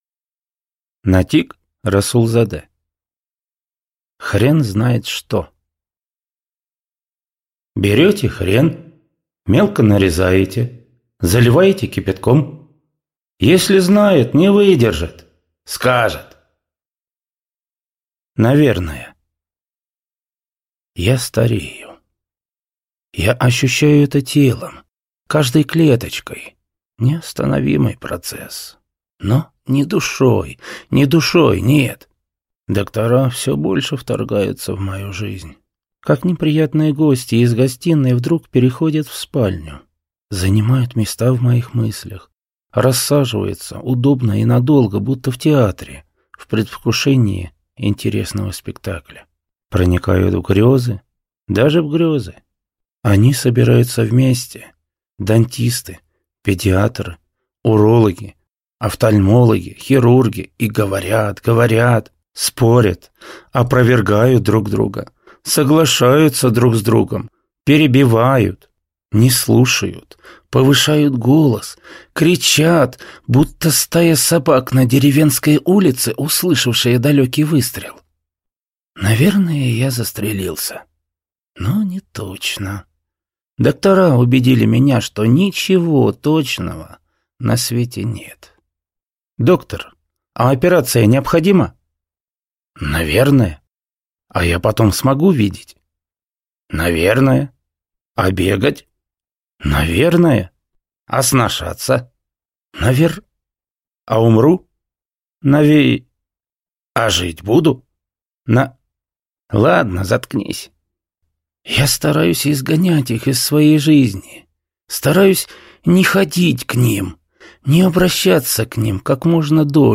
Аудиокнига Хрен знает что!
Прослушать и бесплатно скачать фрагмент аудиокниги